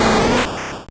Cri de Coconfort dans Pokémon Or et Argent.